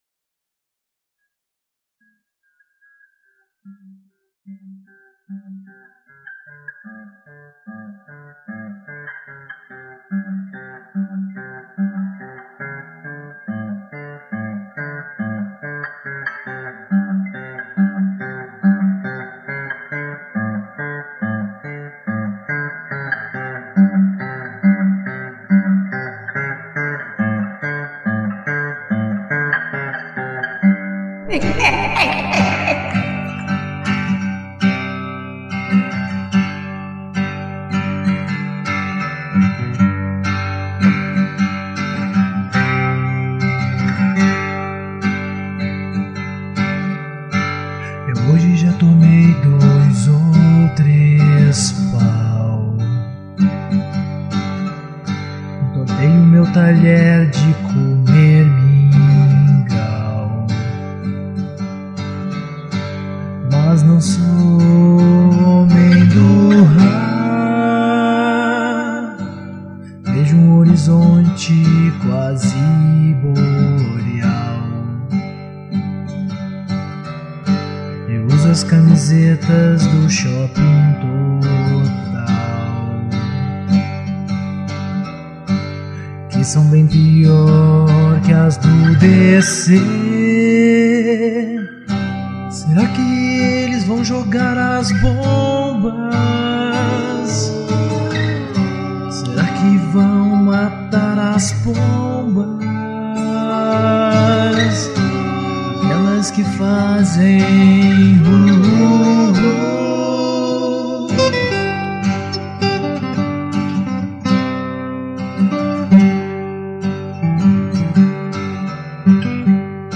EstiloRock